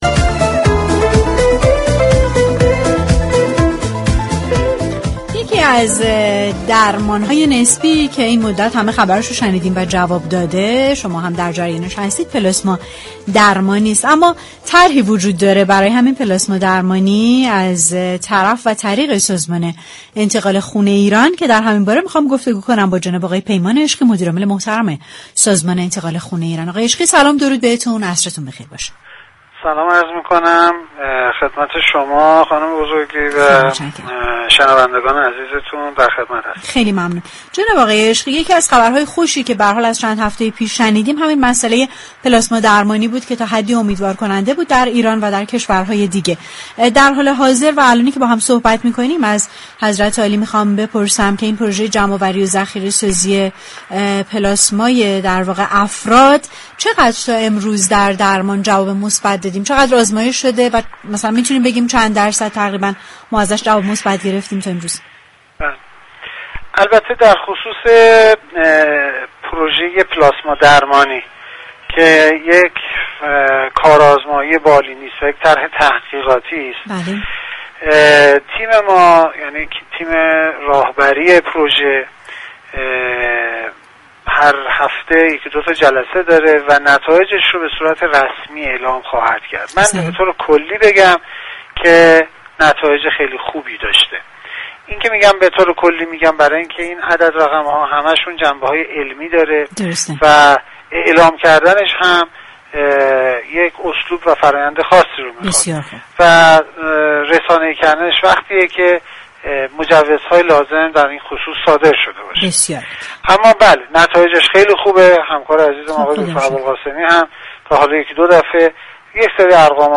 برنامه‌ی رادیویی "تهران من
تماس تلفنی